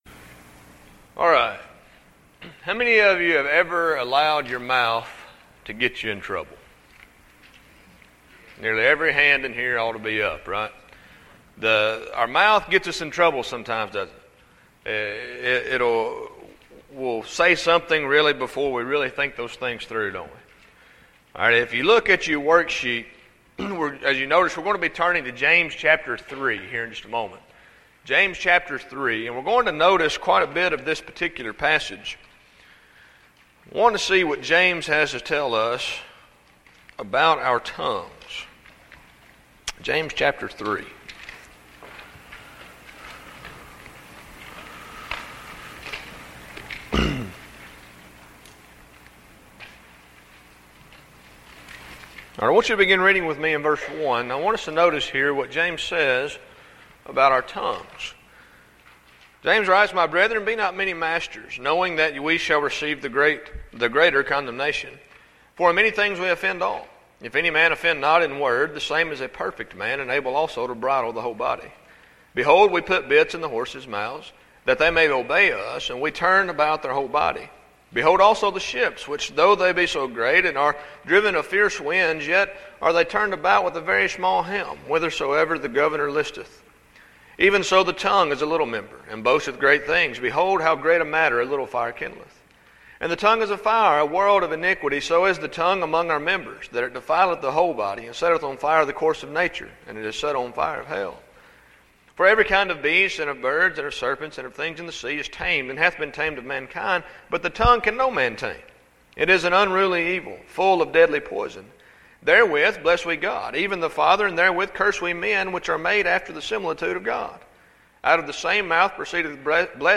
Event: Discipleship University 2012
Youth Sessions